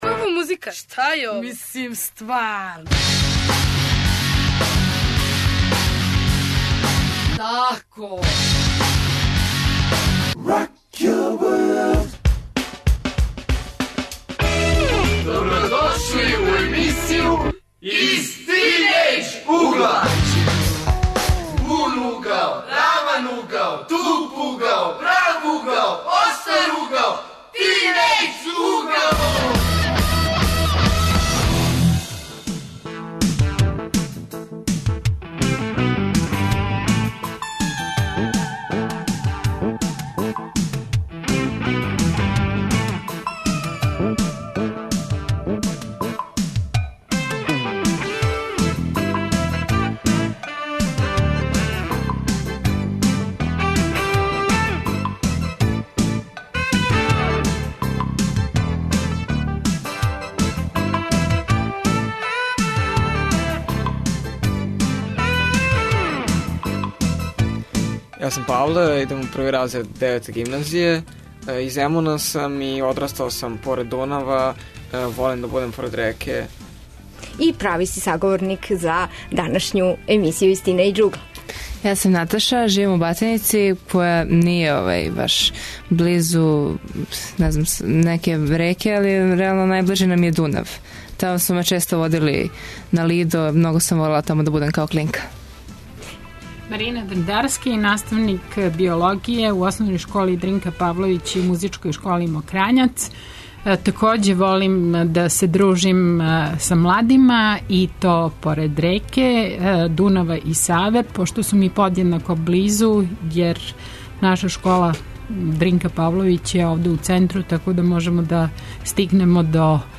Наши гости биће млади из разних градова Србије, који наравно живе поред реке.